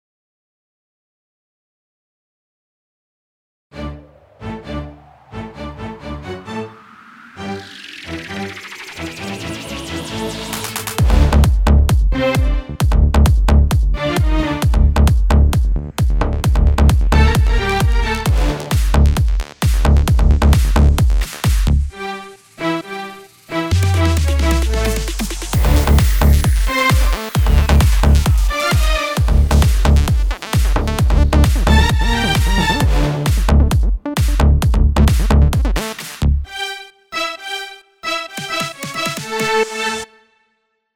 טראק אורגן קורג
קצב טוב, אהבתי את השילוב של המזרחי לאלקטרוני…